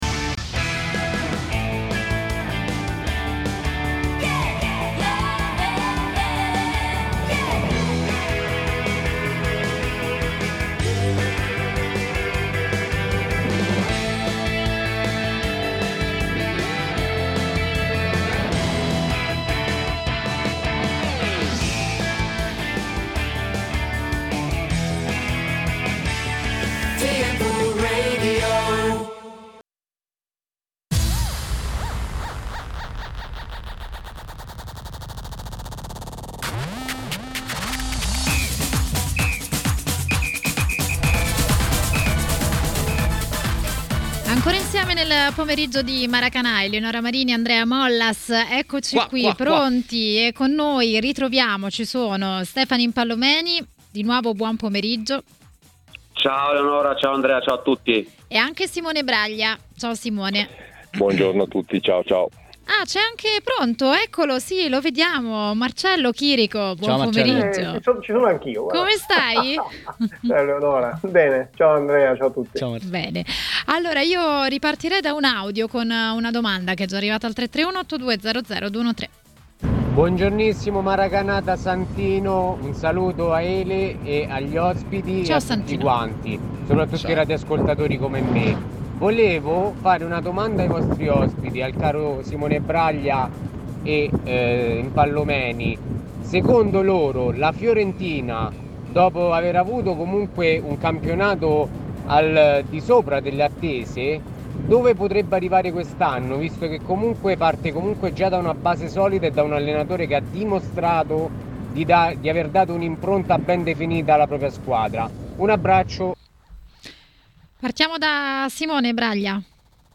ex calciatore e giornalista, ha parlato a Maracanà, trasmissione di TMW Radio, dei temi della Serie A.